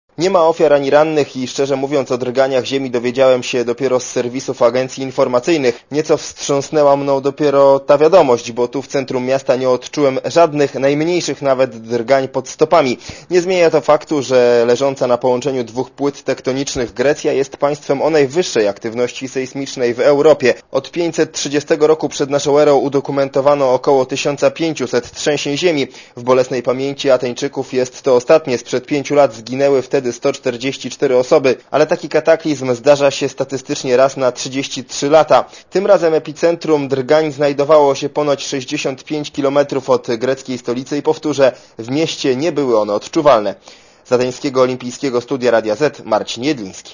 Z olimpijskiego studia w Atenach